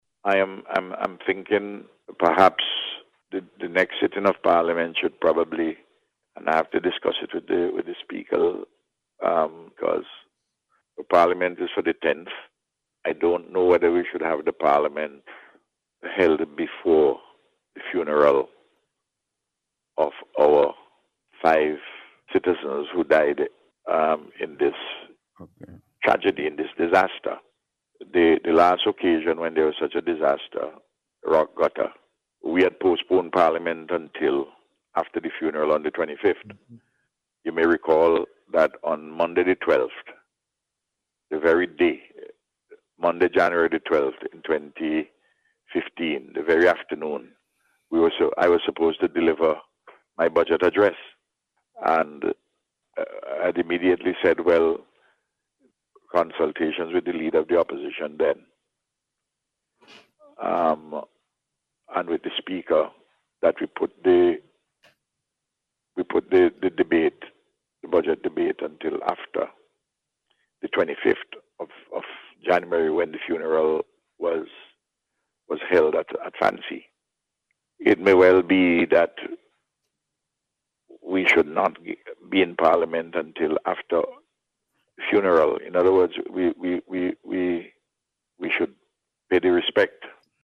This was among a range is issues addressed by Prime Minister Dr. Ralph Gonsalves on Radio on Sunday.